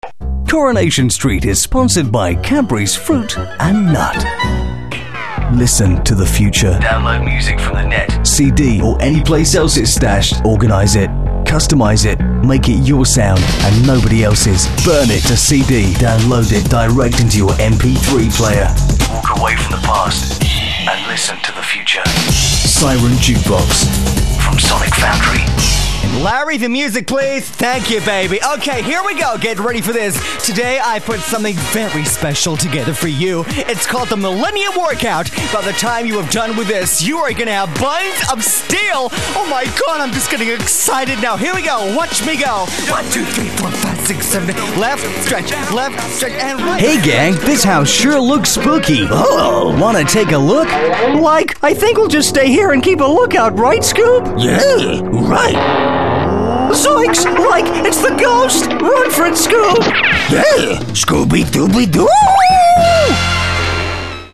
Male Voice Over Artists bkp L can offer the following Male Voice Over artists.
zany, madcap, cartoons, characters, fun